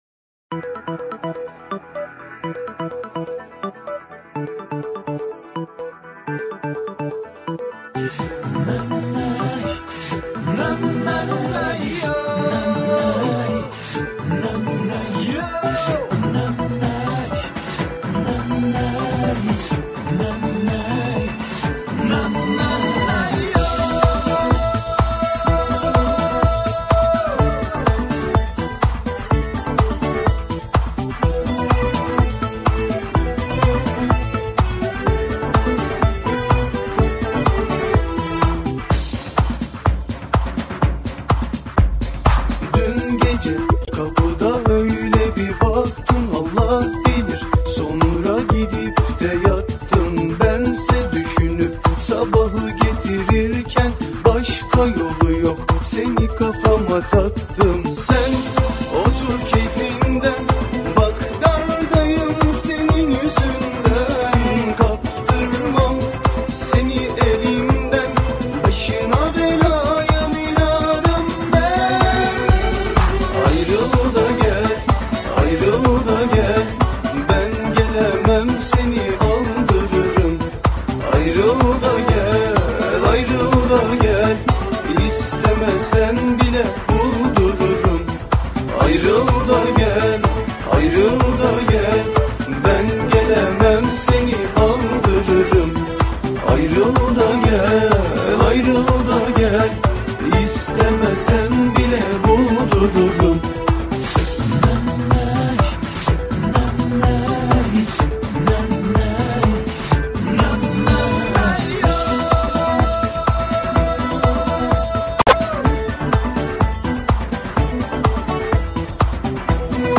ژانر: پاپ ترکی